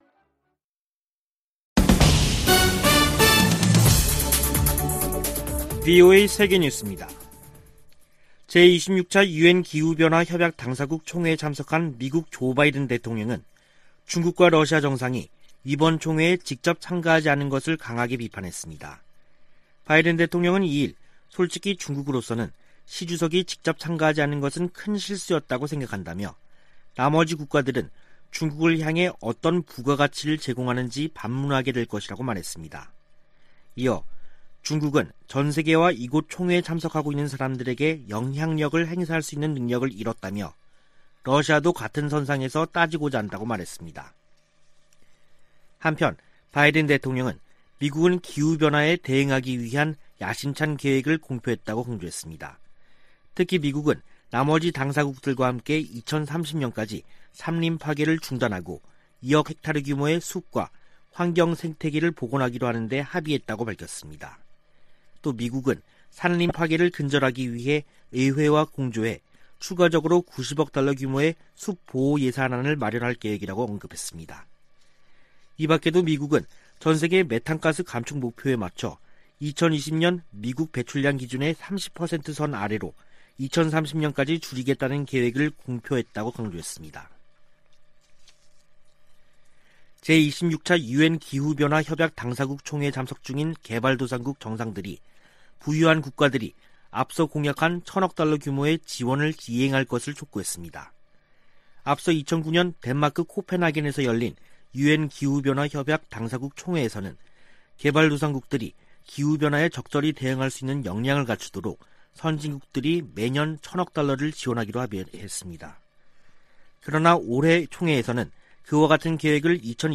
VOA 한국어 간판 뉴스 프로그램 '뉴스 투데이', 2021년 11월 3일 3부 방송입니다. 미 연방법원이 중국 기업과 관계자들의 대북제재 위반 자금에 대해 몰수 판결을 내렸습니다. 중국과 러시아가 유엔 안보리에 다시 대북제재 완화 결의안을 제출한 것은 미국과 한국의 틈을 벌리기 위한 것이라고 미국 전문가들이 분석했습니다. 조 바아든 미국 대통령은 유럽 순방서 '더 나은 세계 재건'을 강조했으나, 한반도 관련 주목할 만한 발언은 없었습니다.